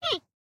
1.21.5 / assets / minecraft / sounds / mob / panda / idle1.ogg